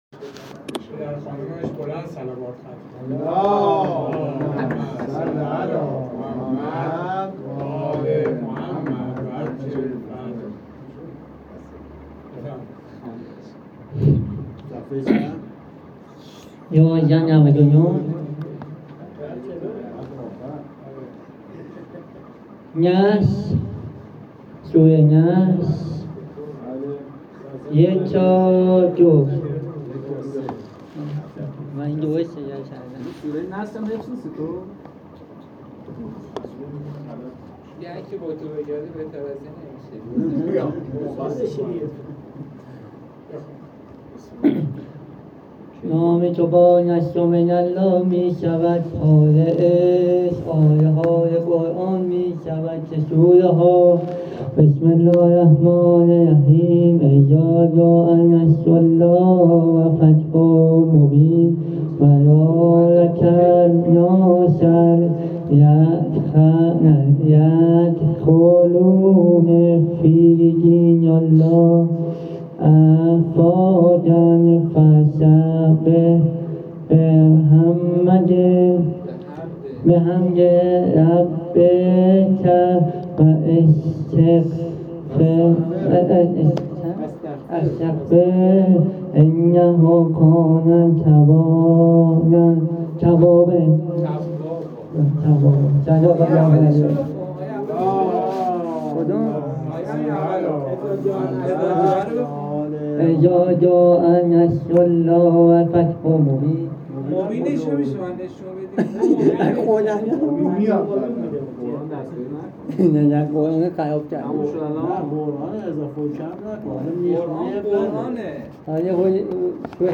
هیئت ابافضل العباس امجدیه تهران